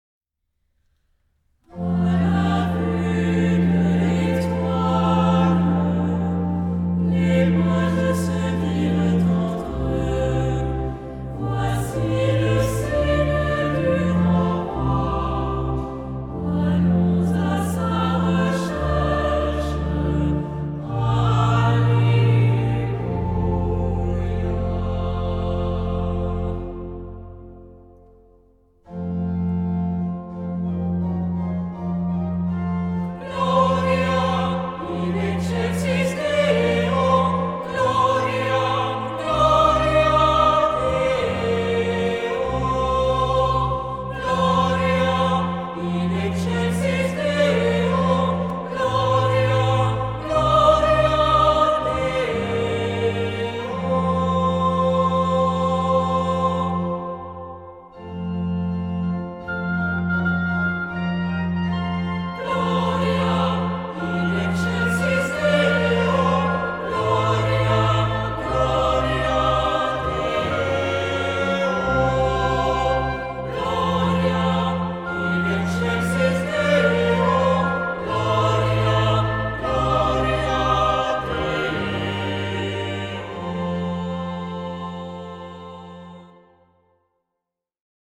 Genre-Style-Forme : Tropaire ; Psalmodie
Caractère de la pièce : recueilli
Type de choeur : SAH OU SATB  (4 voix mixtes )
Instruments : Orgue (1) ; Instrument mélodique (ad lib)
Tonalité : fa majeur